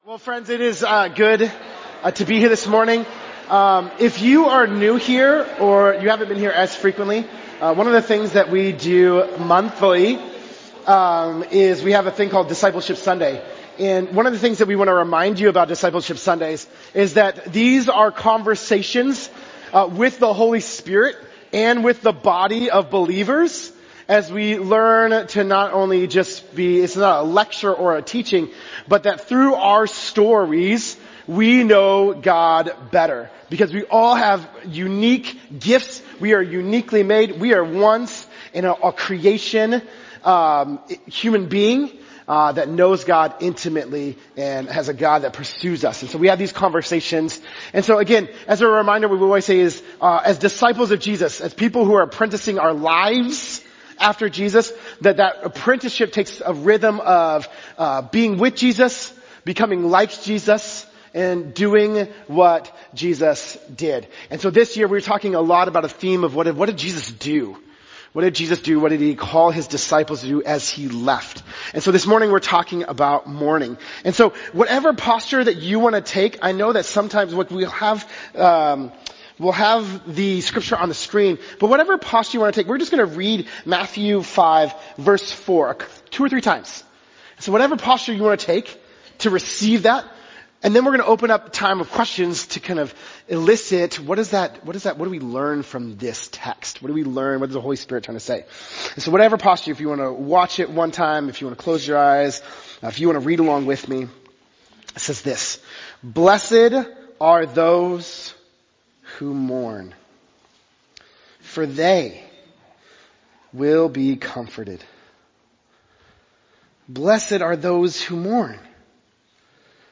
On Discipleship Sundays, we gather as one church family—ages 1 to 103—to learn together and disciple one another, and this month we’ll learn together through Jesus’ first teaching, the sermon on the mount.
Our morning will be interactive and engaging: we’ll explore together, reflect together, and ask honest questions that help us go deeper.